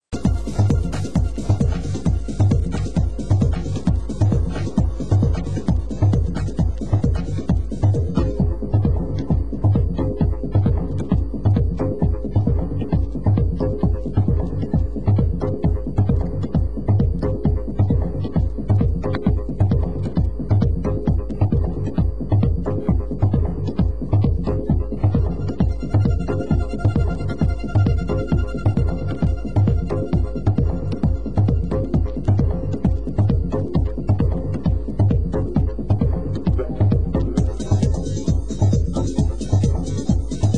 Genre: UK Techno